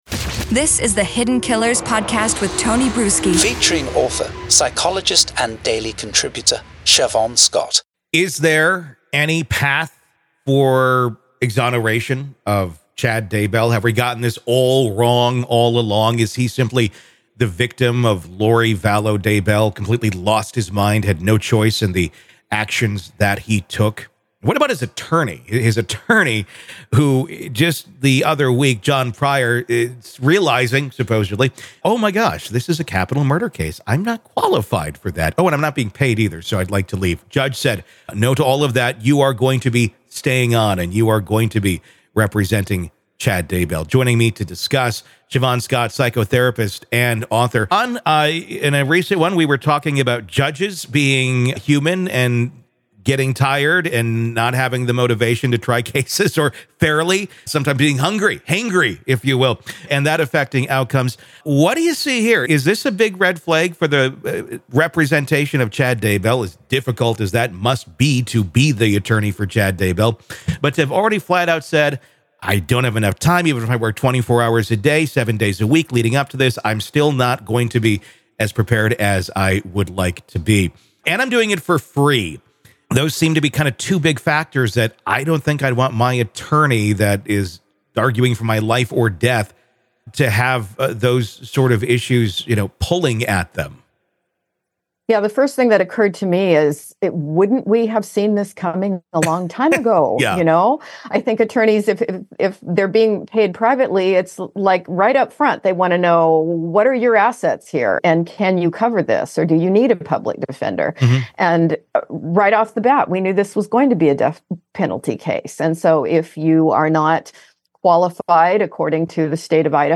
sat down with psychotherapist and author